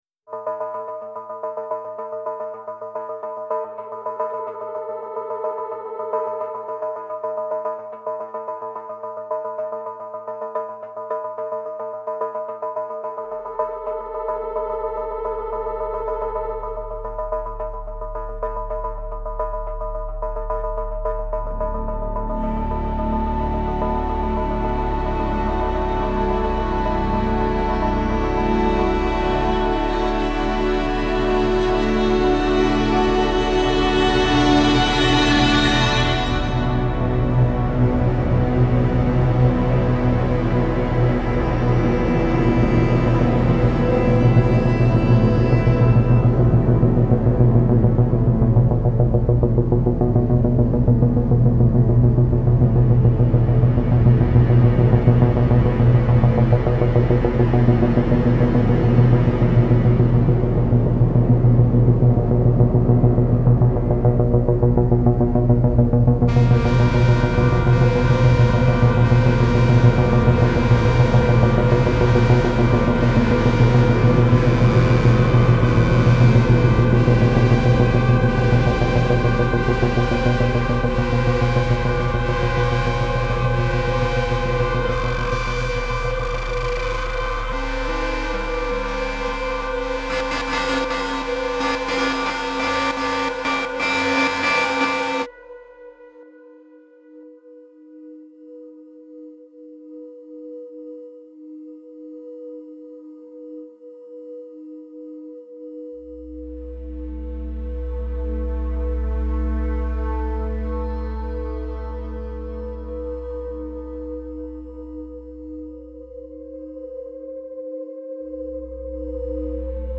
Genre : FilmsGames, Film Scores